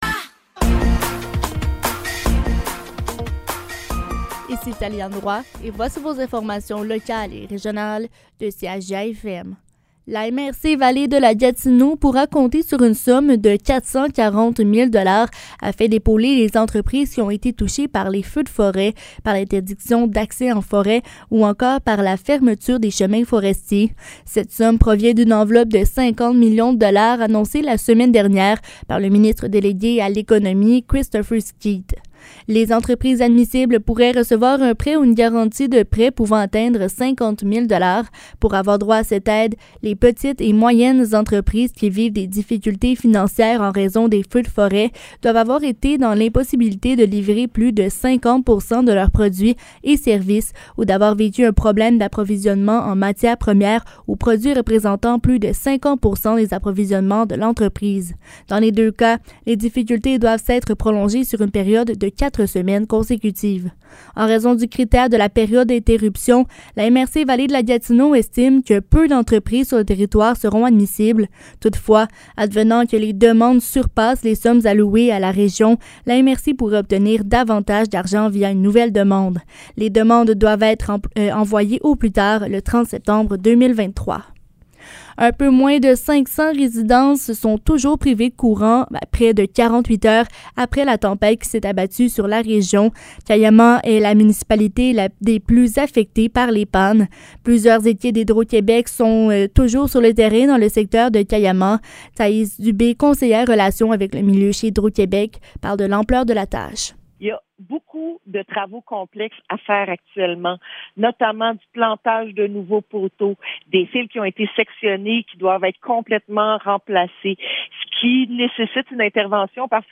Nouvelles locales - 13 juillet 2023 - 15 h